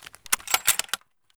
bolt_unjam.ogg